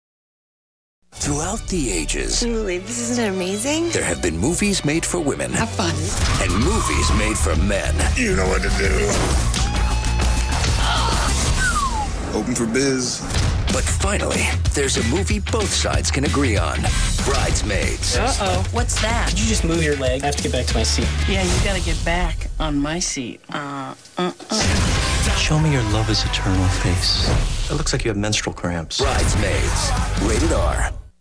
Brides Maids TV Spots